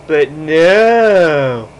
But Nooo Sound Effect
Download a high-quality but nooo sound effect.
but-nooo-1.mp3